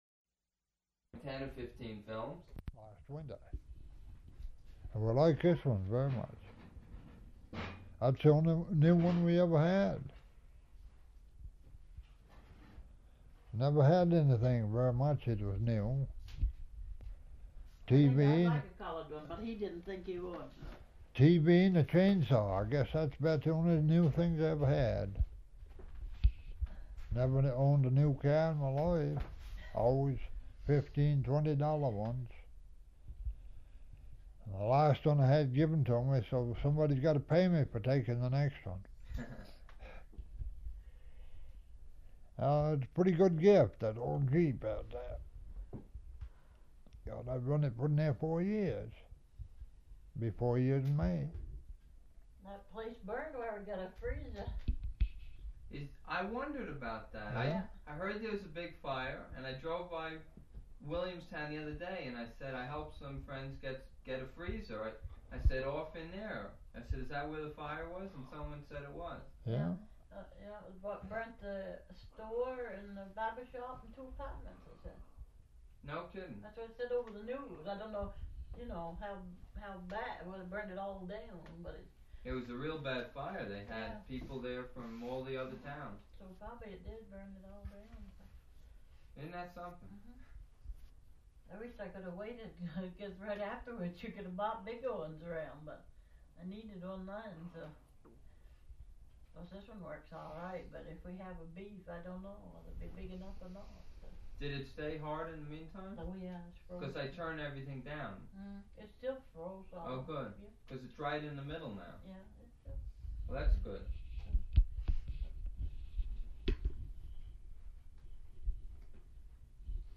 Interview
Format 1 sound tape reel (Scotch 3M 208 polyester) : analog ; 7 1/2 ips, full track, mono.